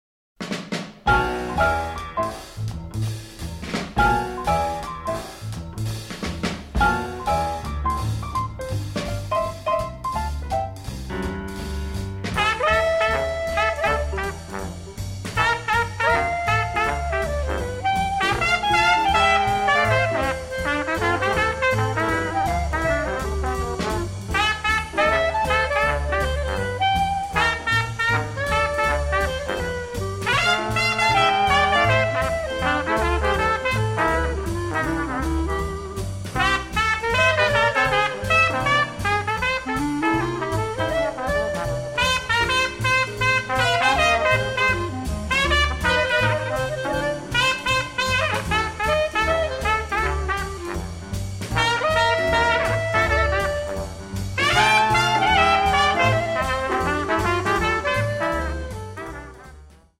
60s Jazz Music Vinyl